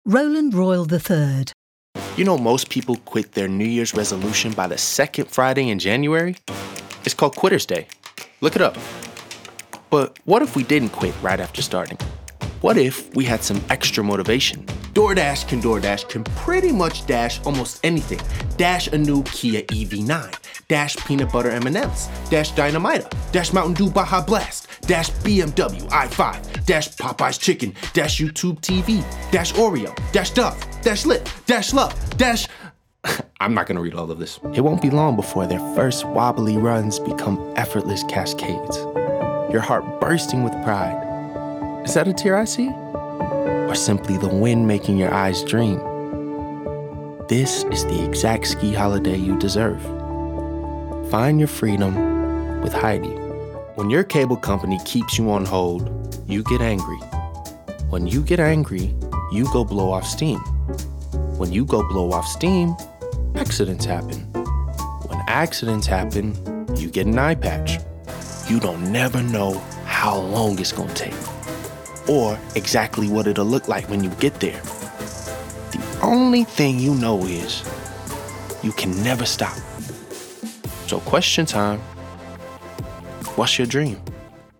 Description: American: confident, contemporary, authentic
Age range: 20s - 30s
Commercial 0:00 / 0:00
American*